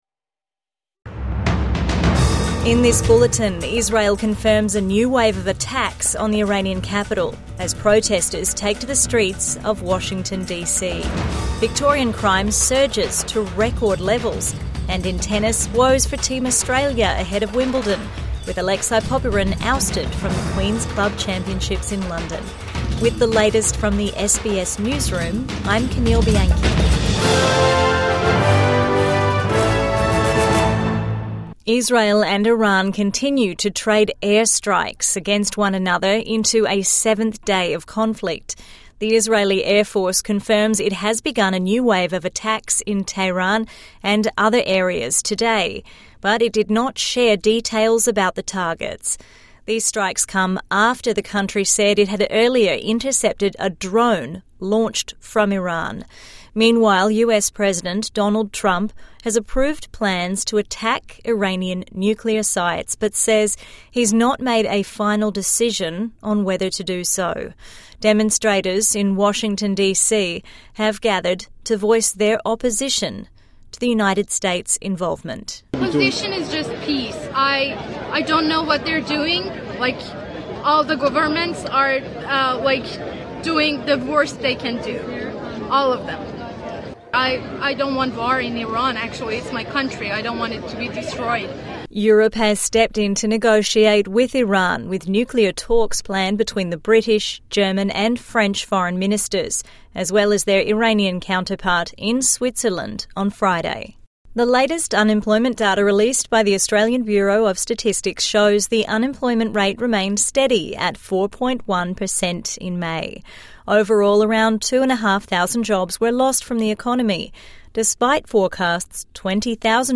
Protests over US involvement in Iran, Israel conflict | Midday News Bulletin 19 June 2025